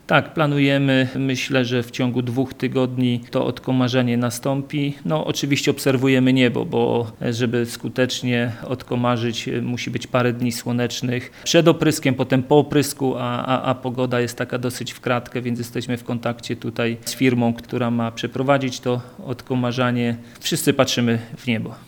Mówi prezydent Mielca Jacek Wiśniewski.